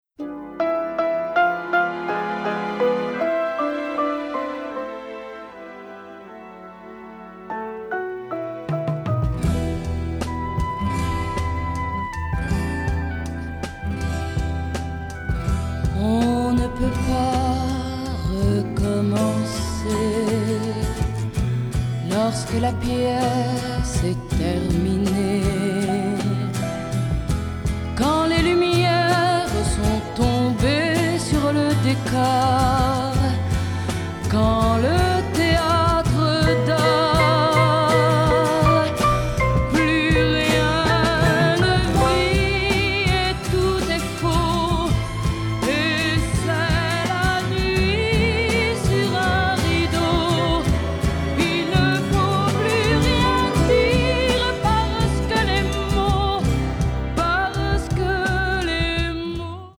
psychedelic cult classics